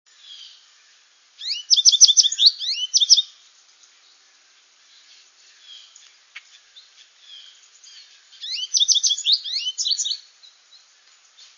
Goldfinch
Wellfleet, Cape Cod, MA Maurice's Campground, 8/8/02, (44kb), another song variation